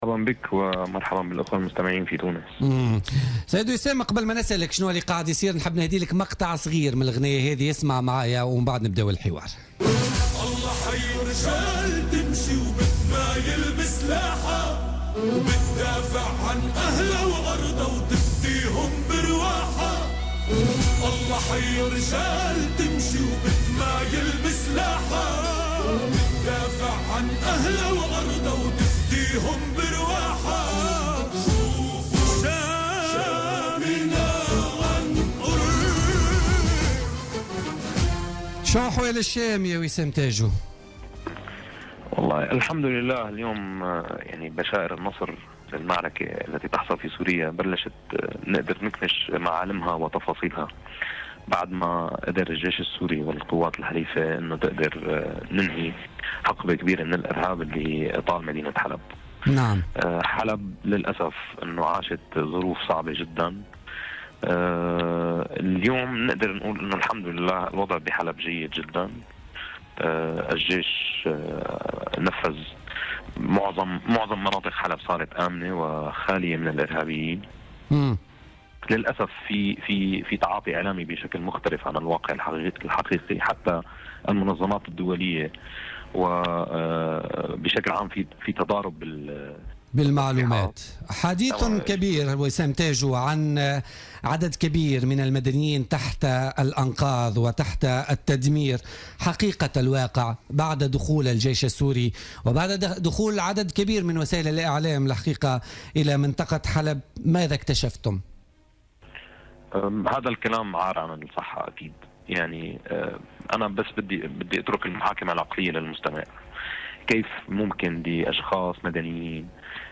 اعلامي سوري : بشائر النصر لاحت في سوريا
مداخلة له في بوليتيكا